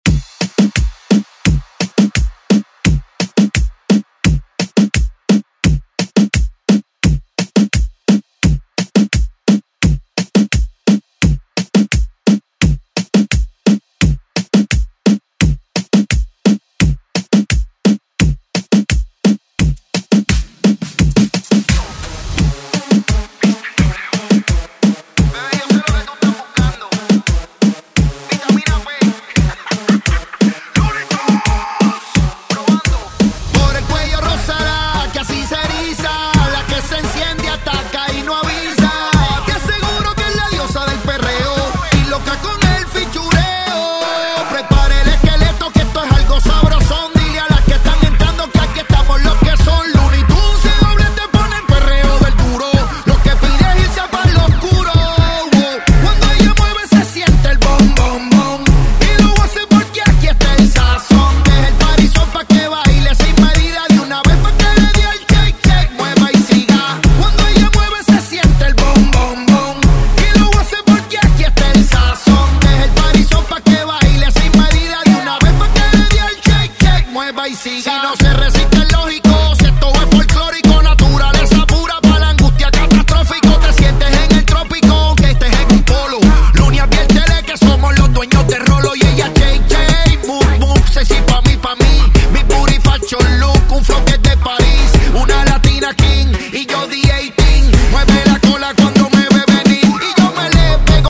Genres: LATIN , RE-DRUM
Clean BPM: 134 Time